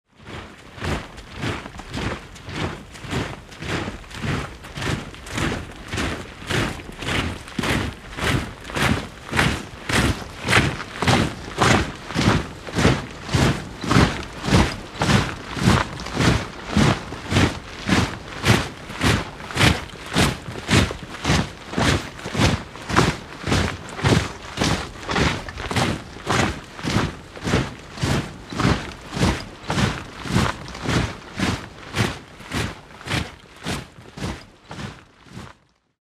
Звуки марша солдат
Рота солдат шагает спокойным строем в столовую